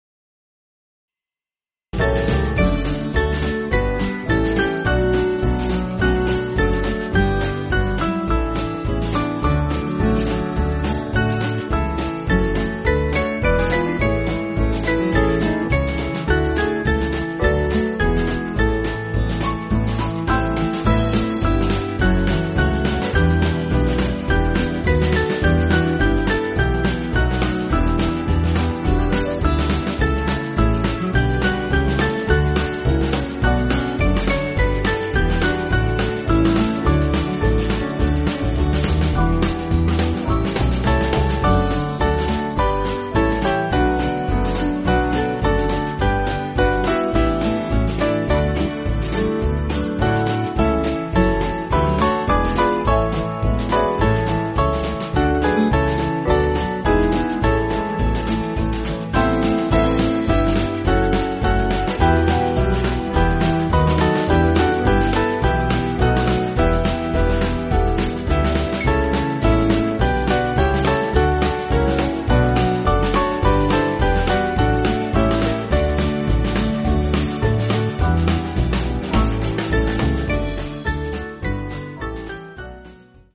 7.6.7.6.D
Small Band